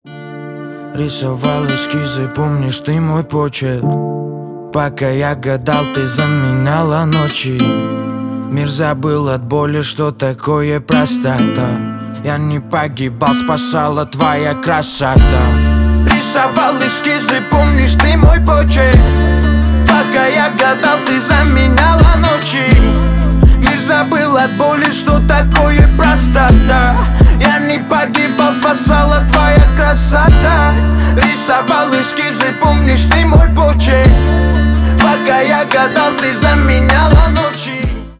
Рэп и Хип Хоп
грустные # спокойные